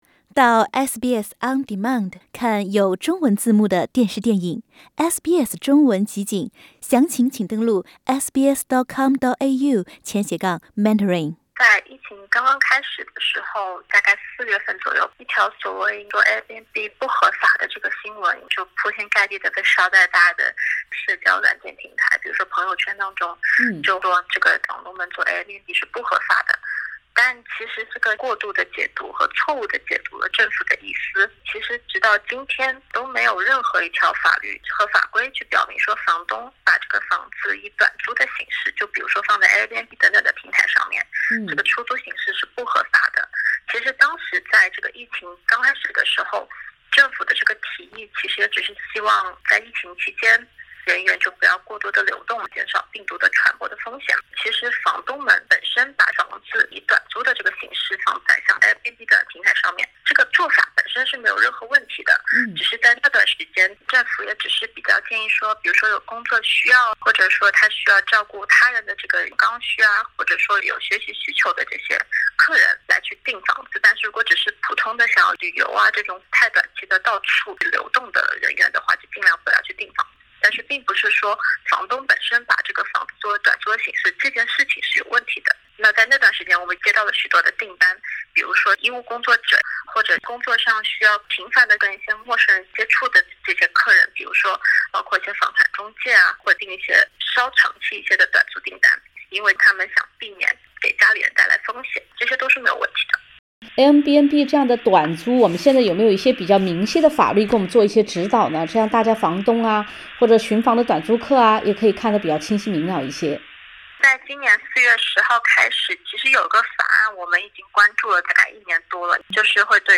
请收听本台记者对澳洲短租市场的近况采访。